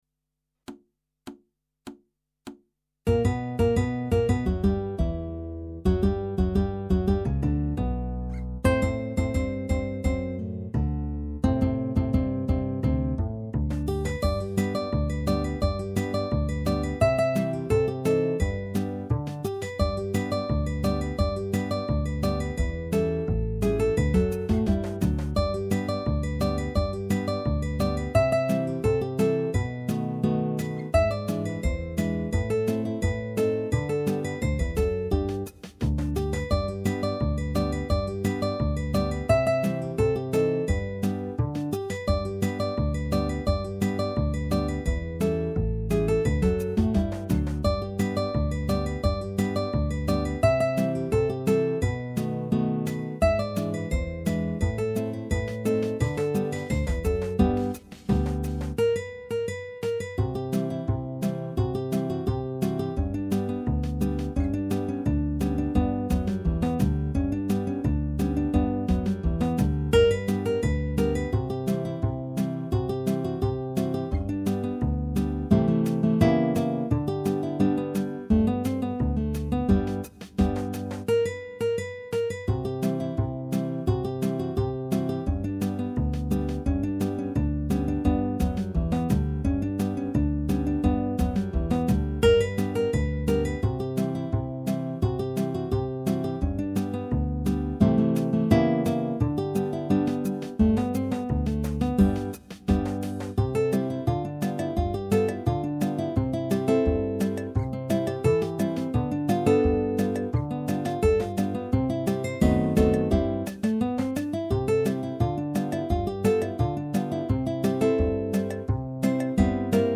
minus Guitar 2